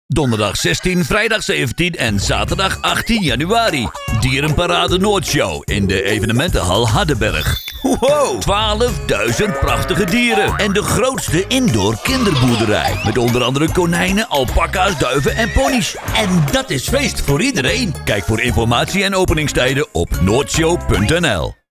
Radiospotje Dierenparade Noordshow | Noordshow